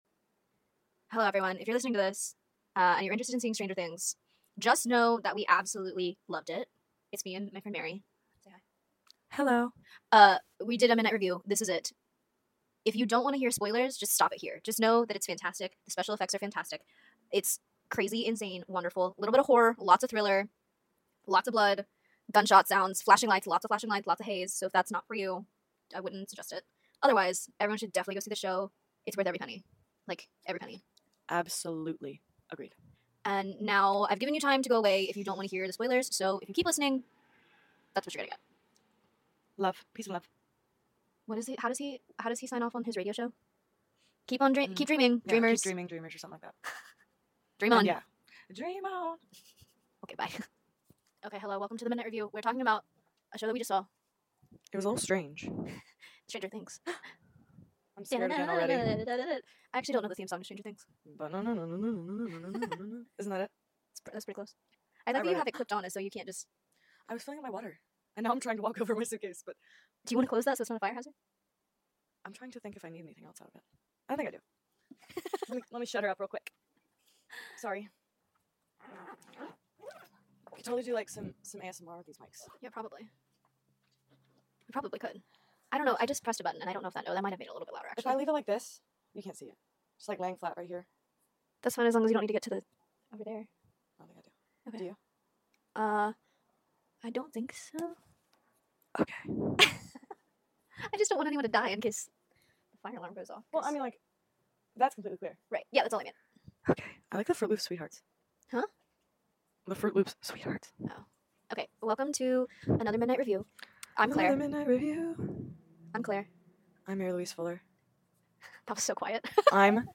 We sat down at 10:30 pm right after we got home to record this.